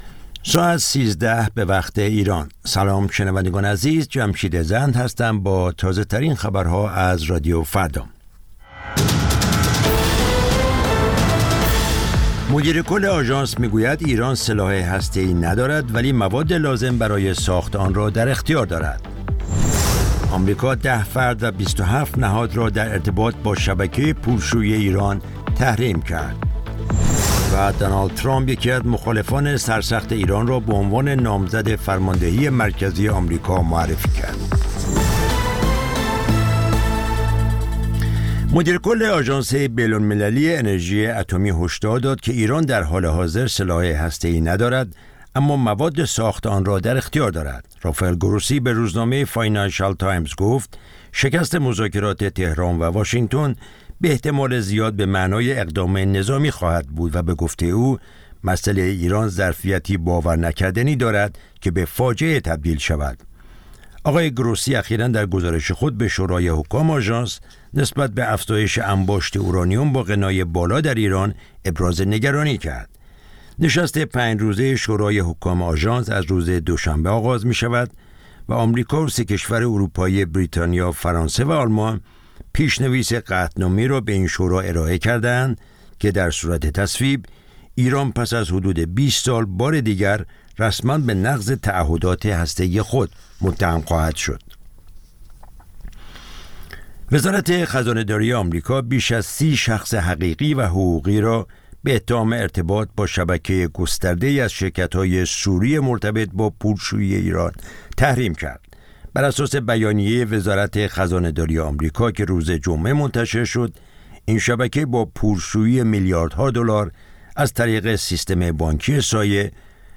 سرخط خبرها ۱۳:۰۰